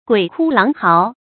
鬼哭狼嗥 注音： ㄍㄨㄟˇ ㄎㄨ ㄌㄤˊ ㄏㄠˊ 讀音讀法： 意思解釋： ①同「鬼哭神號」。